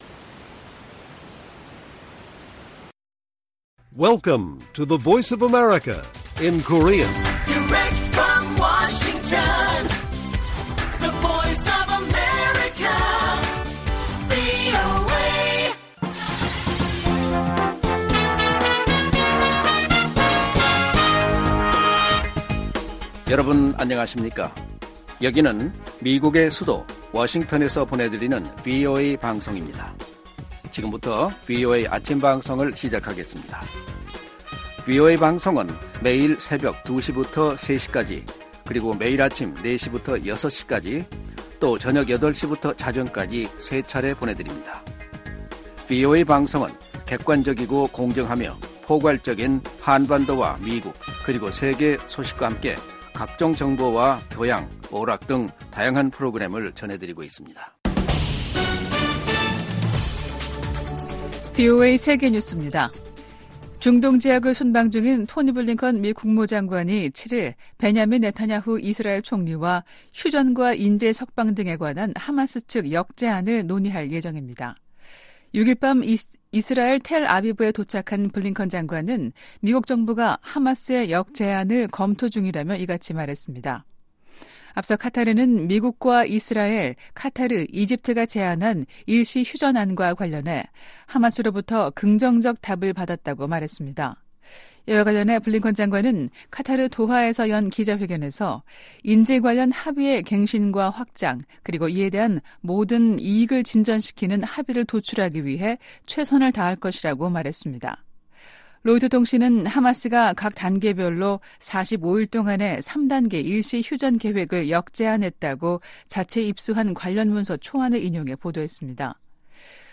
세계 뉴스와 함께 미국의 모든 것을 소개하는 '생방송 여기는 워싱턴입니다', 2024년 2월 8일 아침 방송입니다. '지구촌 오늘'에서는 하마스가 이집트와 카타르 등이 중재한 휴전안에 답을 전달한 소식 전해드리고, '아메리카 나우'에서는 알레한드로 마요르카스 국토안보부 장관 탄핵소추안이 하원에서 부결된 이야기 살펴보겠습니다.